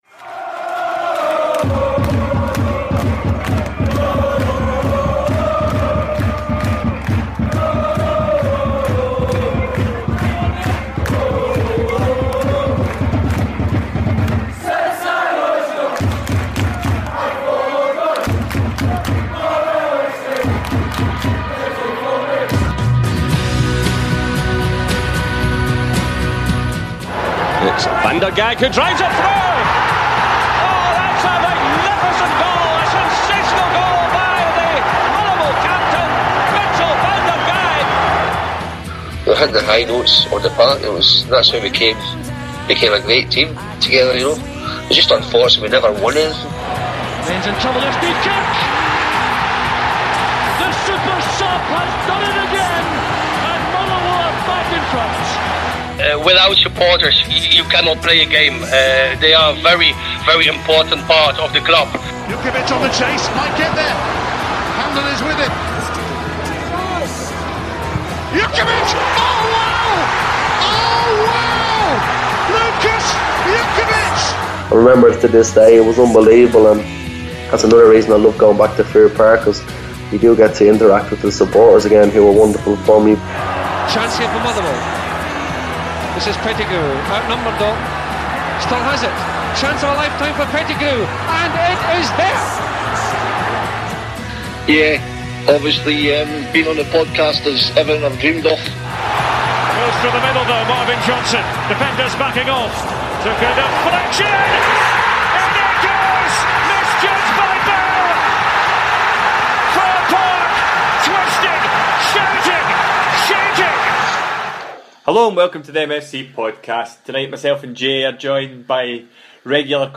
Due to using new recording software this week there are a few instances where the sound quality isn’t to the usual standard – we apoligise for this and will do our best to have this resolved by next week’s episode.